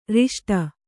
♪ riṣṭa